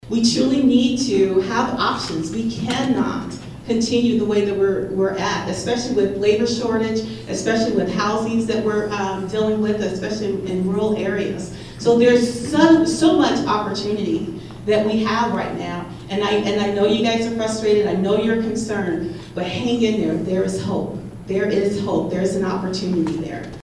Republican House & Senate candidates speak in Atlantic Monday evening
(Atlantic, Iowa) – Cass County Republicans hosted a “Know Your Candidates” forum Monday evening, at the Cass County Community Center in Atlantic.